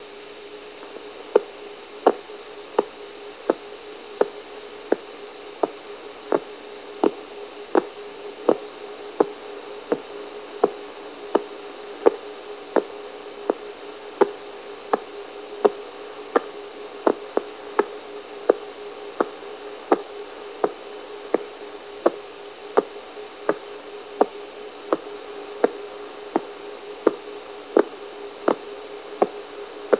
The following links to .WAV files will allow you to hear the audio frequency modulation of radio noise generated by pulsars. If your .WAV file player has a scope function on it you will be able to see the regular nature of the waveforms.